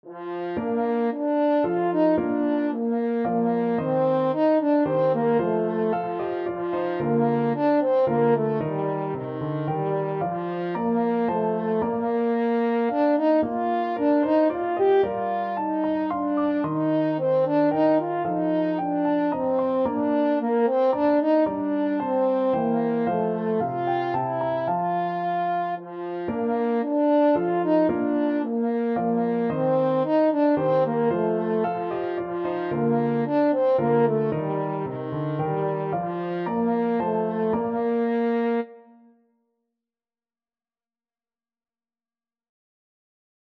French Horn
Traditional Music of unknown author.
Moderately Fast ( = c. 112)
Eb4-G5
3/4 (View more 3/4 Music)
Classical (View more Classical French Horn Music)